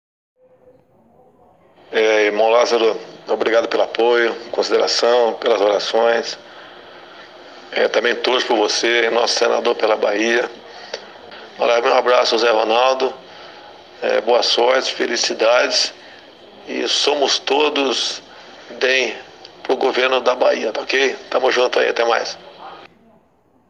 O candidato ao Senado Irmão Lázaro divulgou um áudio gravado no hospital pelo presidenciável Jair Bolsonaro em apoio a ele e ao candidato ao governo da Bahia pelo DEM, José Ronaldo.